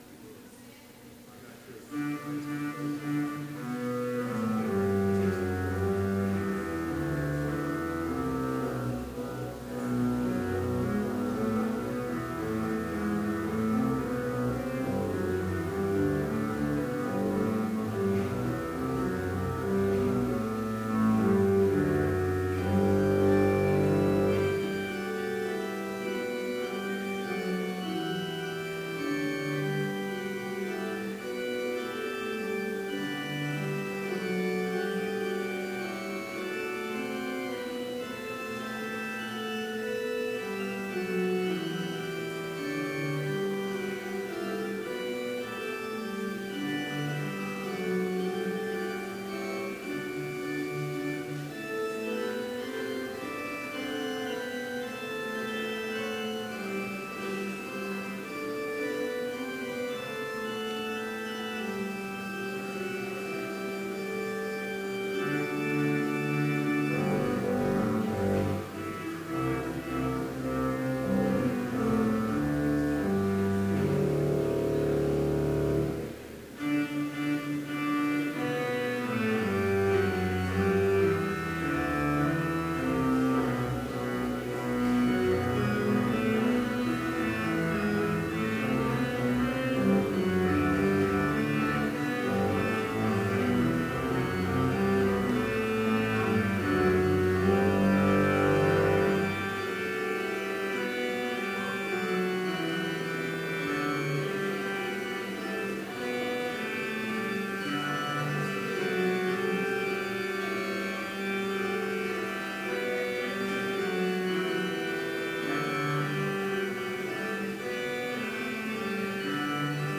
Complete service audio for Chapel - October 6, 2016